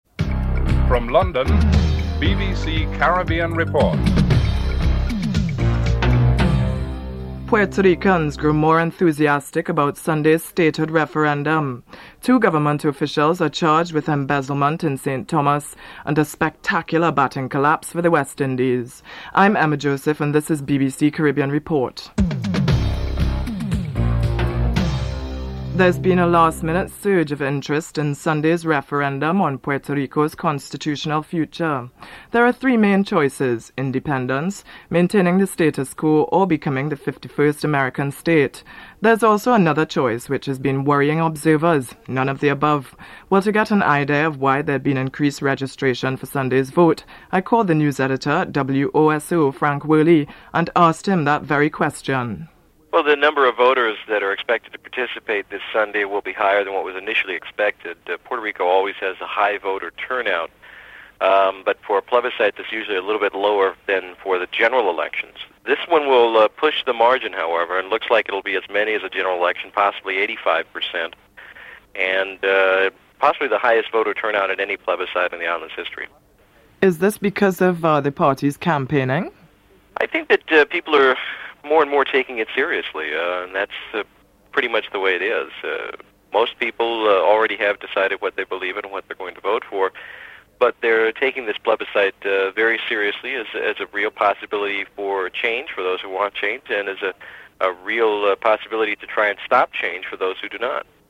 Headlines
6. Spectacular batting collapse for the West Indies Cricket Team. Former Fast Bowler Colin Croft reports (11:59-15:04)